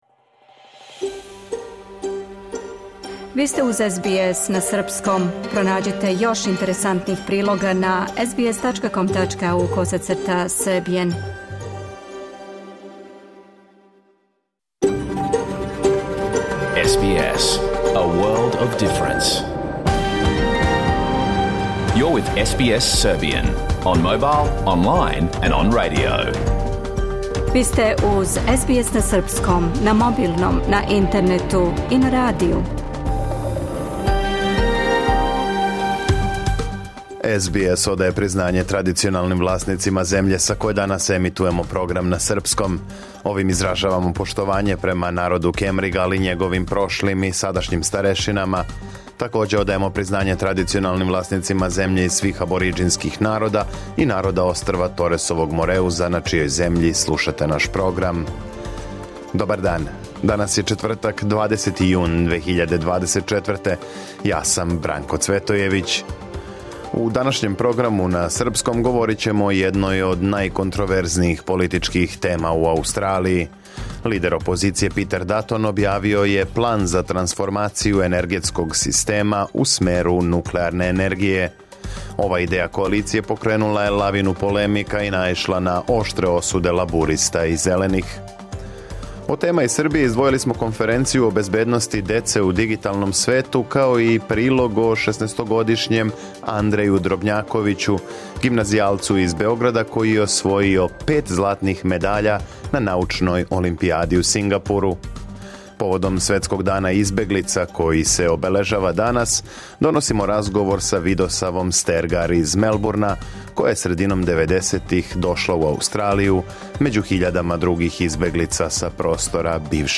Програм емитован уживо 20. јуна 2024. године
Уколико сте пропустили данашњу емисију, можете је послушати у целини као подкаст, без реклама.